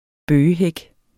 Udtale [ ˈbøːjə- ]